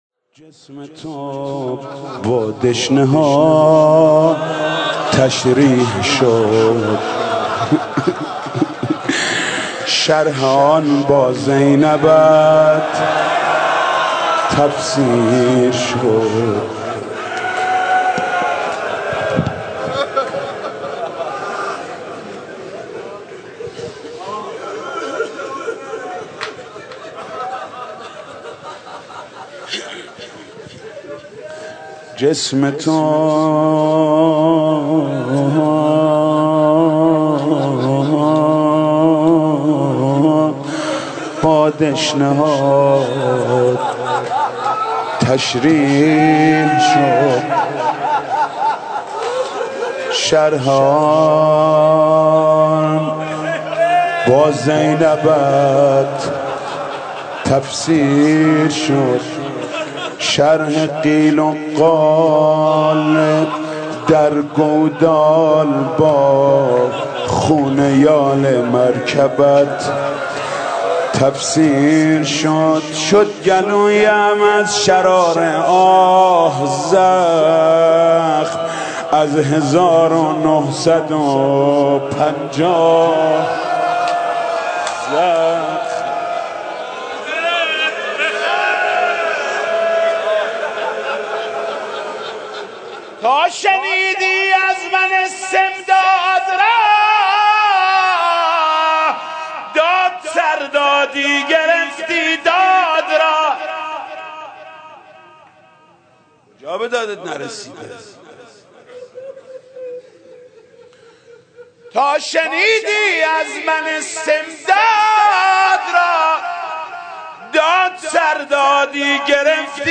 جسم تو با دشنه ها تشریح شد (روضه ، سال 94،جدید)